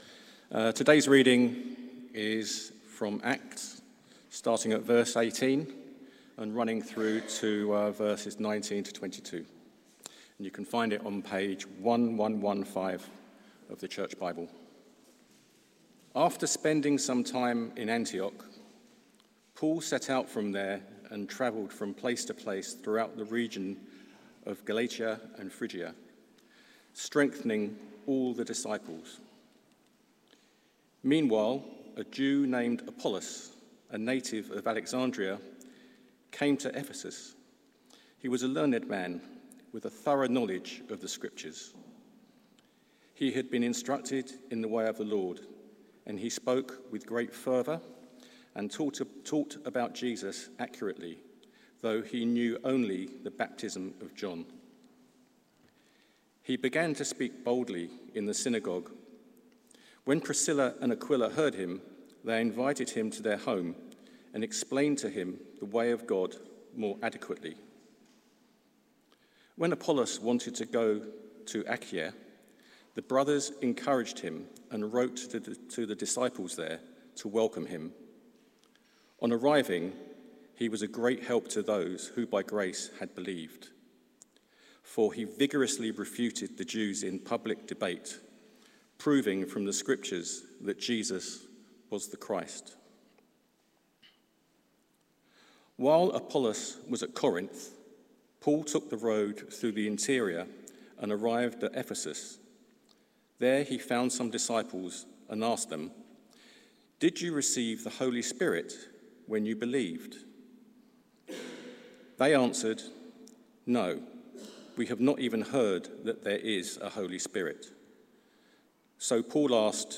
Series: Acts: Mission Unstoppable! Theme: God's Power in Ephesus Sermon Search: